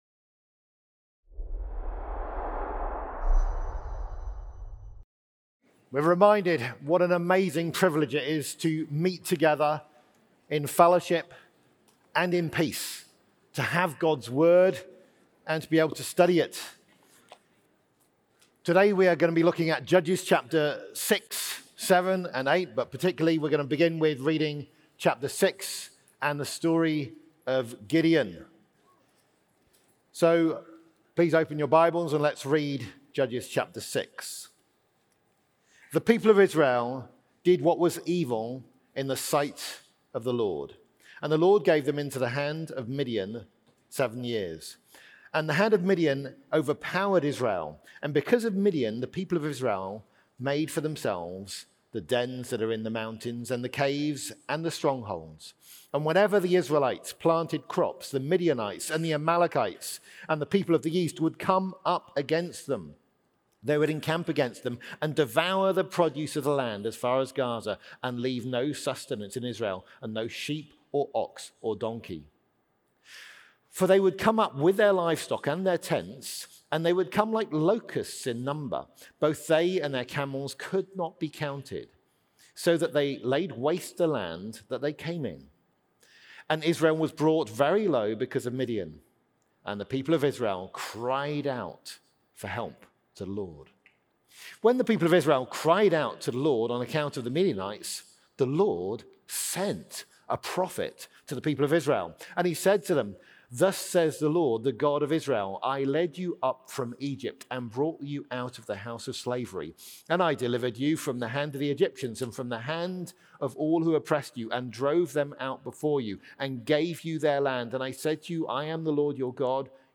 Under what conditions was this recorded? Event: ELF Plenary Session